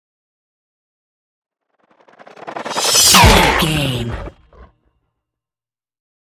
Whoosh metal sword
Sound Effects
Atonal
whoosh